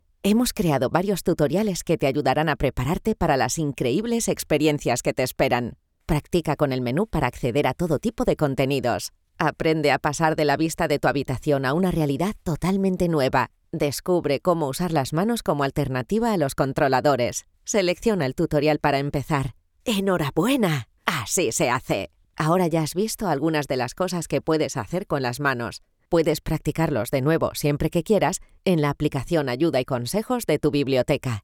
Natürlich, Vielseitig, Tief, Zugänglich, Warm
Erklärvideo
Ich biete eine hohe Klangqualität mit schneller Umsetzung und kann meine Stimme Ihrem Projekt anpassen.